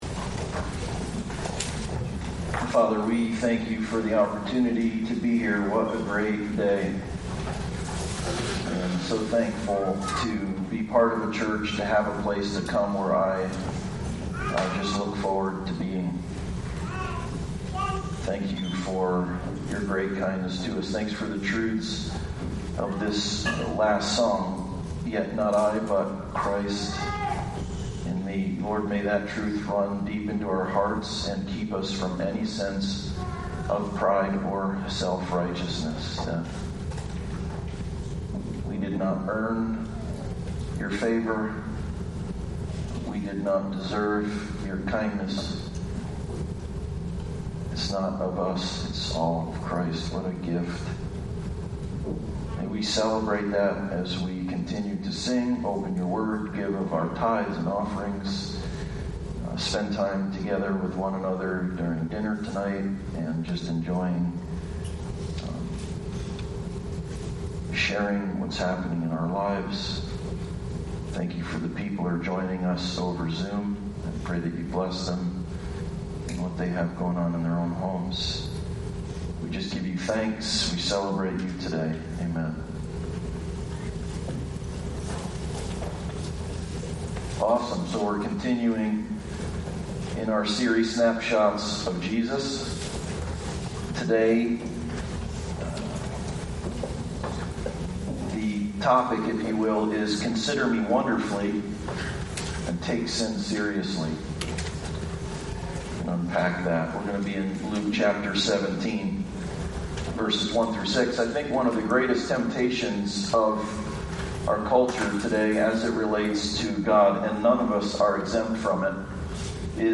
Passage: Luke 17:1-6 Service Type: Sunday Service